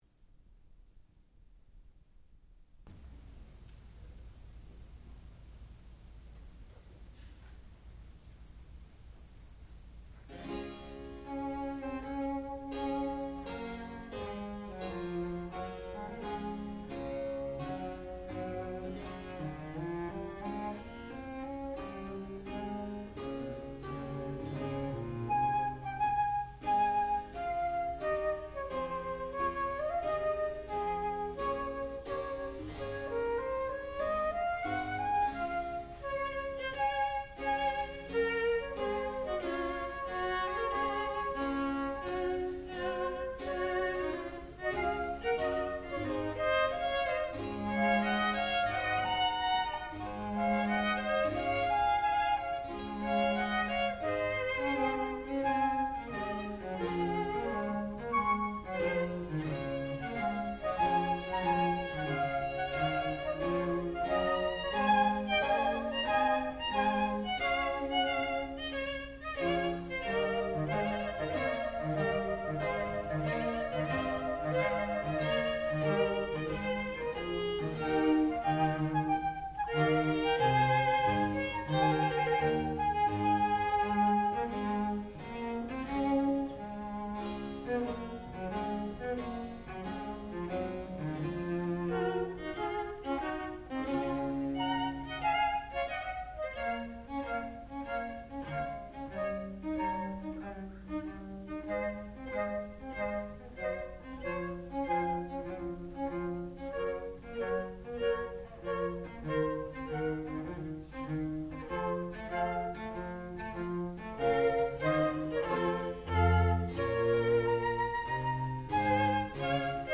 Sonata In D Major for Flute, Violin, Violincello and continuo
sound recording-musical
classical music
Cambiata Soloists (performer).